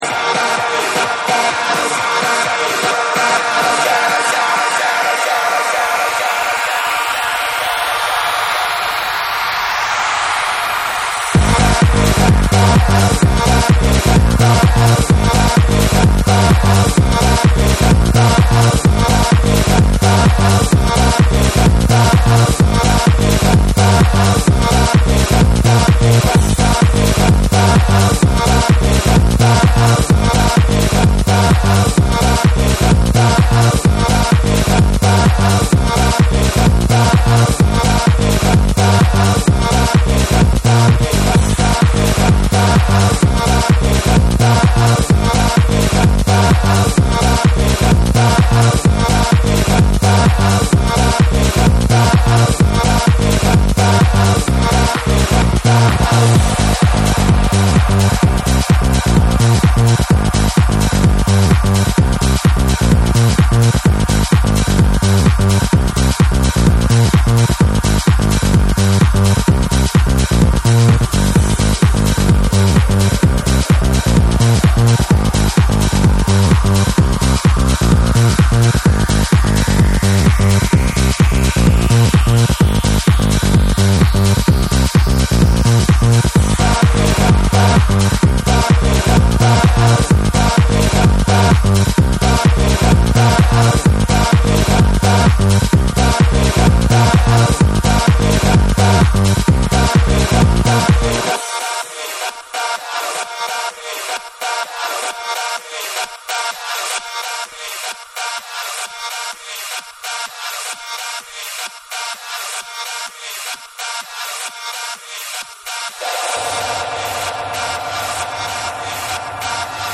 原曲の破壊力はそのまま、さらにボトムを強化しDJユースに仕上げたフロア盛り上がり必至のバースト・チューン！
NEW WAVE & ROCK / TECHNO & HOUSE / RE-EDIT / MASH UP